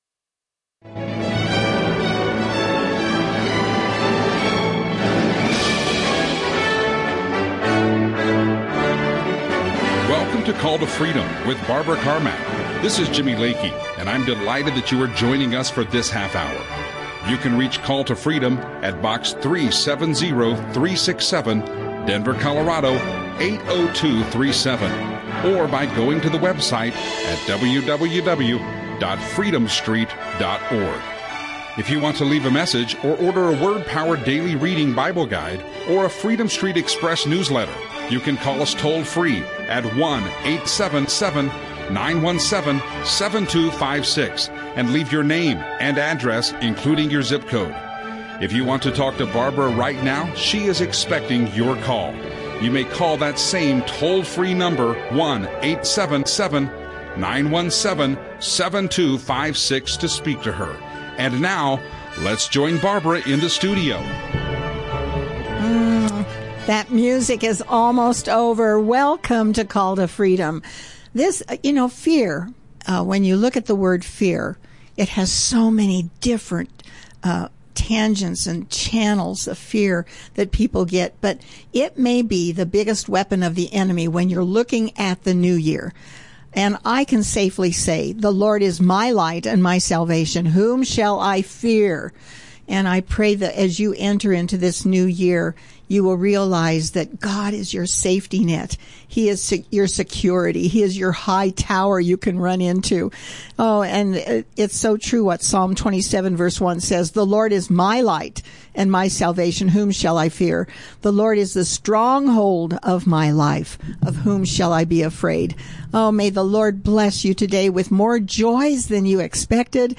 Audio teachings
Christian radio